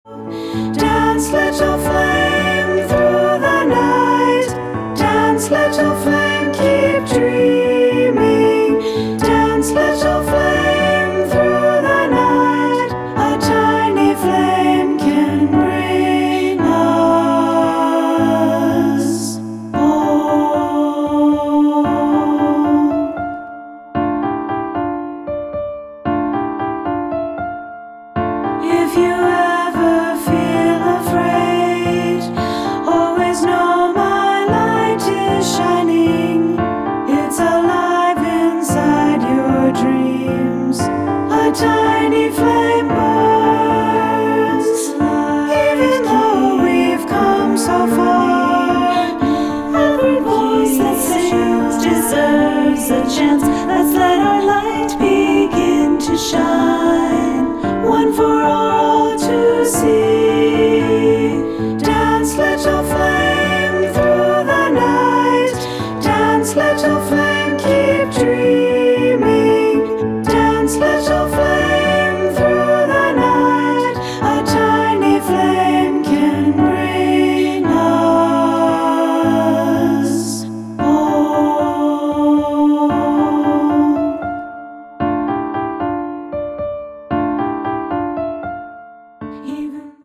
Unis/2 Part